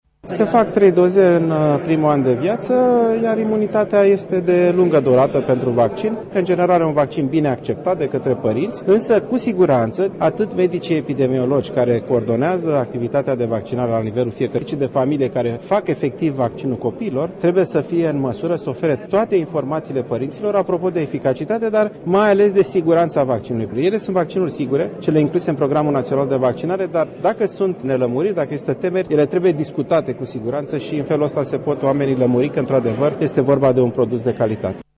Preşedintele Societăţii Române de Microbiologie, profesorul doctor Alexandru Rafila, spune că acest tip de vaccin este sigur şi a fost introdus în schema de imunizare a copiilor în majoritatea ţărilor europene: